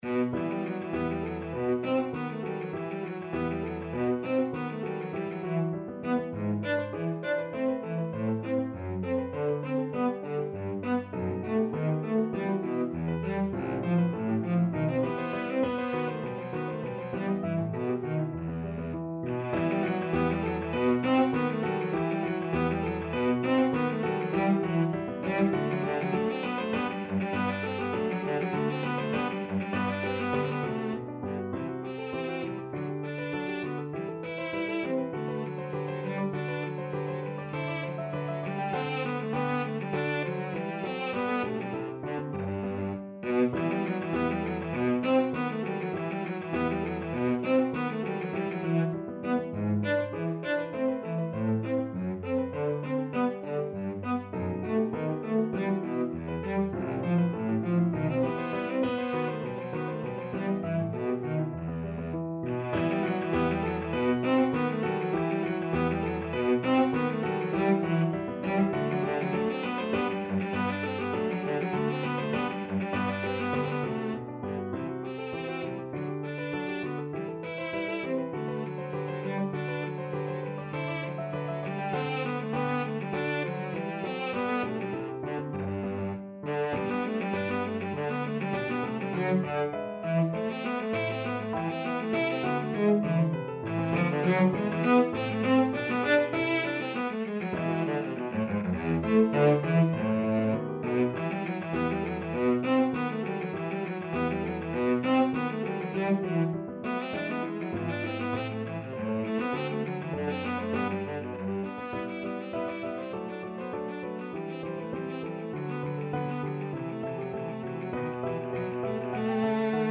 Cello
4/4 (View more 4/4 Music)
~ = 100 Allegro (View more music marked Allegro)
Classical (View more Classical Cello Music)